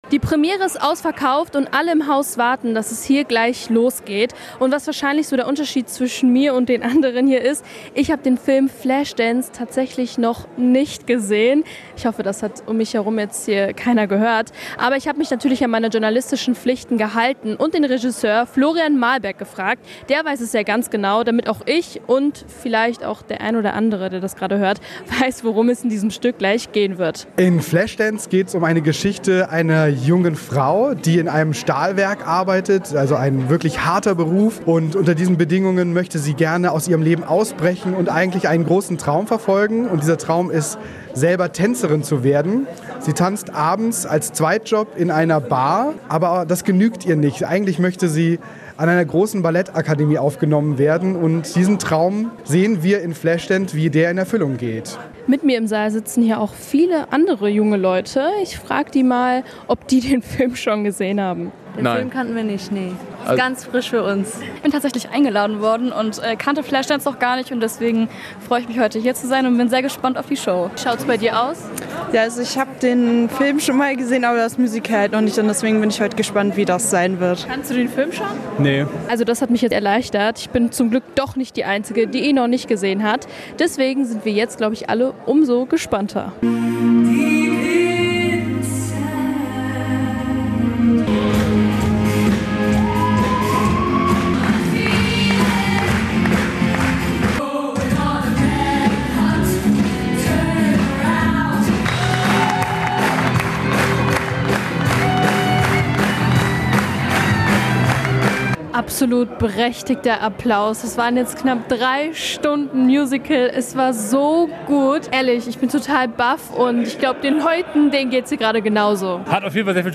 reportage-premiere-flashdance.mp3